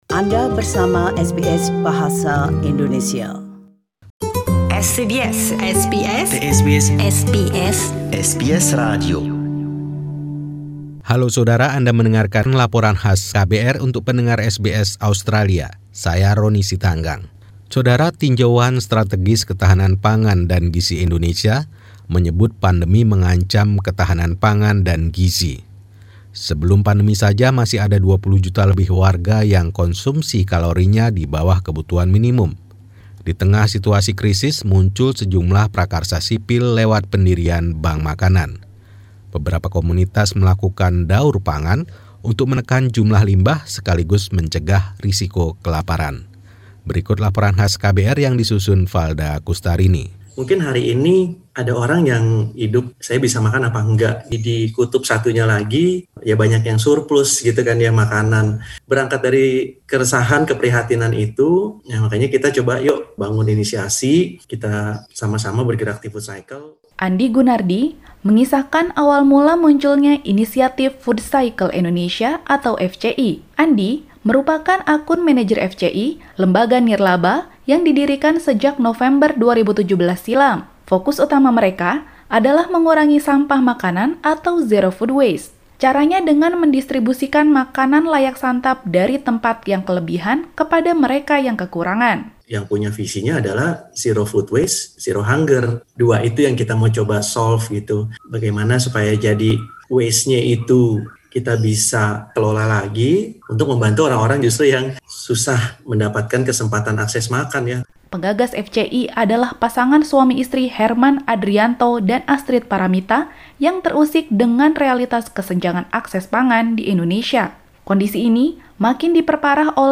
The team at KBR 68H report on how the groups manage.